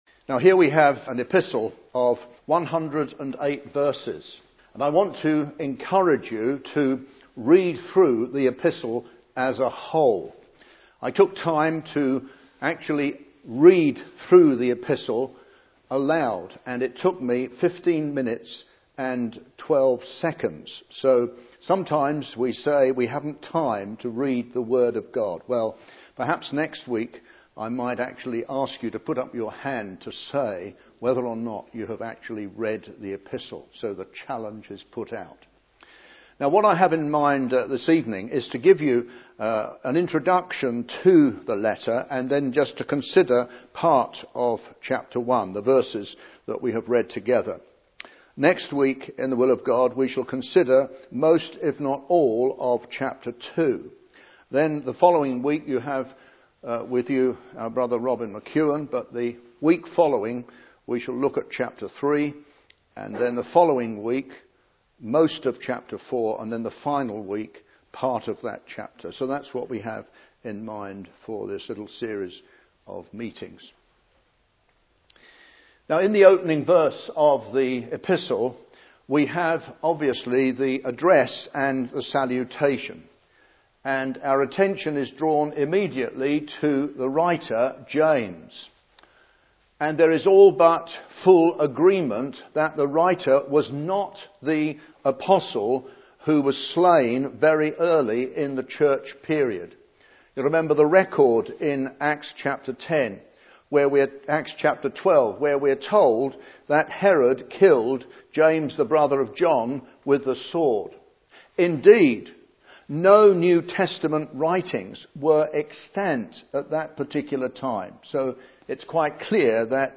Message preached 7th May 2009